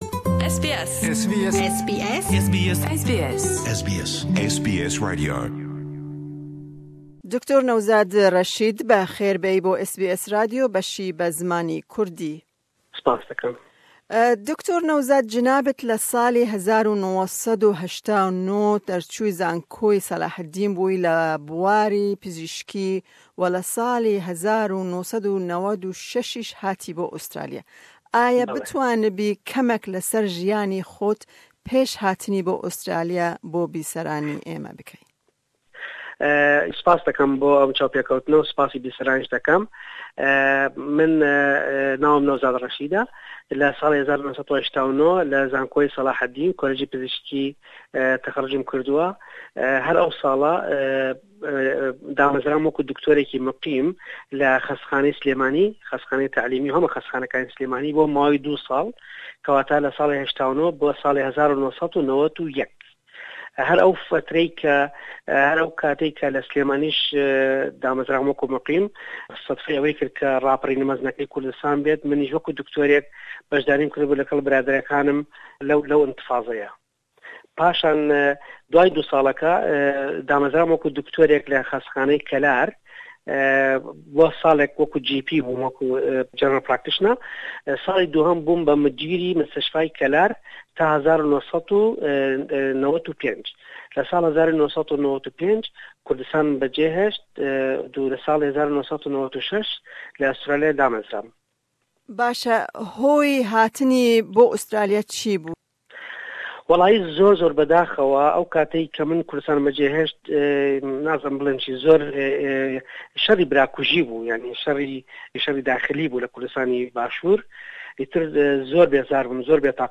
Me hevpeyvînek derbarê jiyana wî li Australya pêk anî.